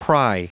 Prononciation du mot pry en anglais (fichier audio)
pry.wav